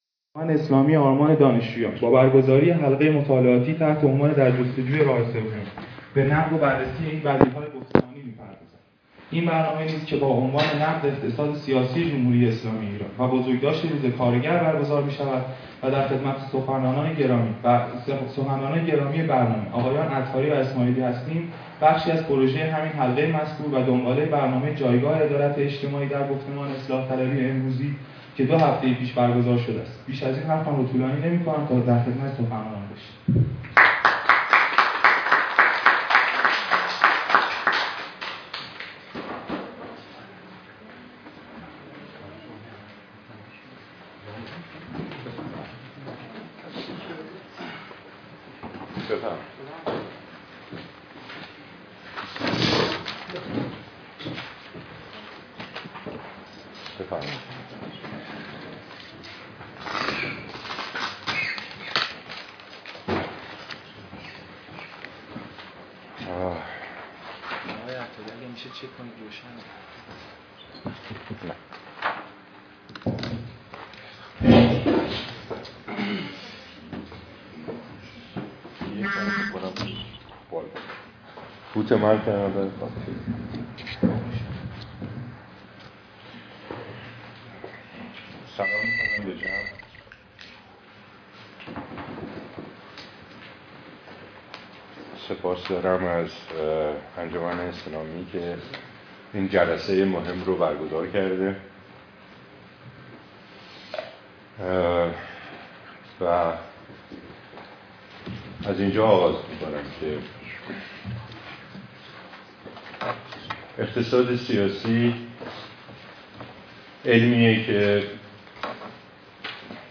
ادامه ---------------------------------------------------------------- به خود زحمت بدهید، دانلد کنید و بشونید و با جامعه امروز ایران آشنا شوید سخنرانی ها و پرسش و پاسخ های دانشگاهی را گوش کنید!
sokhanrani.mp3